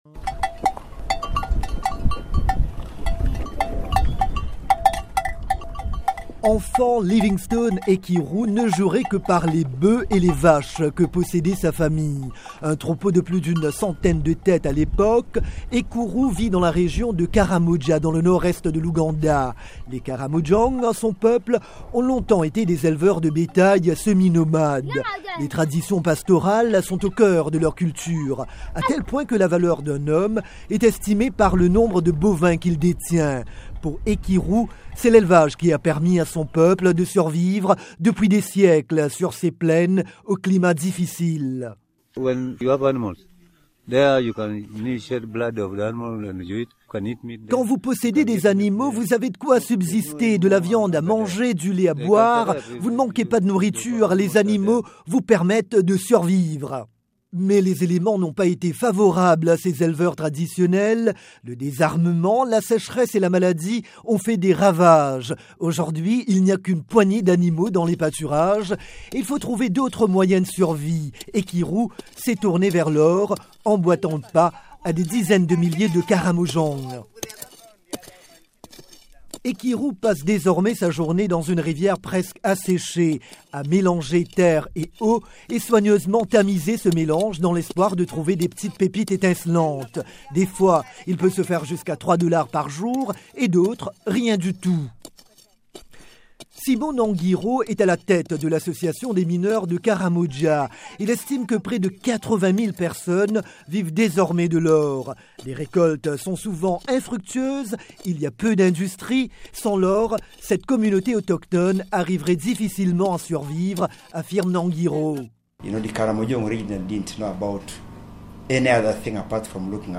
Mais leur nouvelle méthode de survie est aujourd’hui menacée par les gros appétits miniers qu’attire Karamoja. Sur place, le reportage